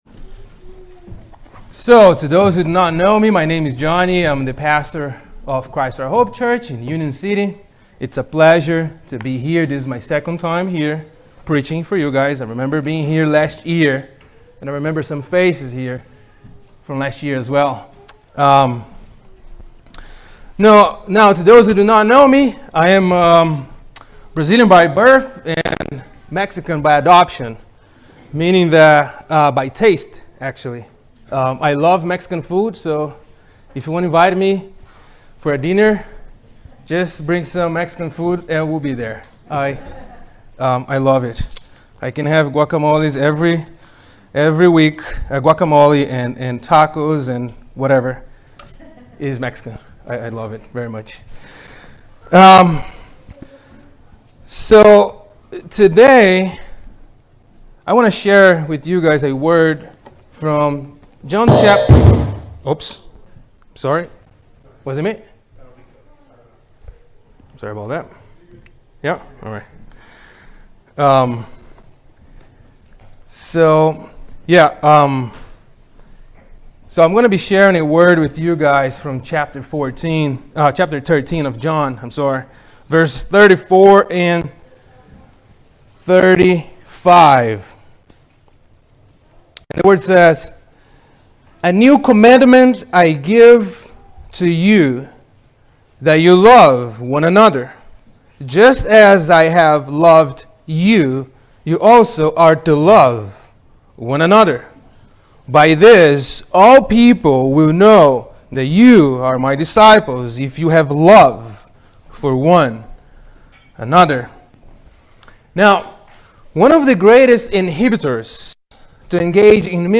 A New Commandment | SermonAudio Broadcaster is Live View the Live Stream Share this sermon Disabled by adblocker Copy URL Copied!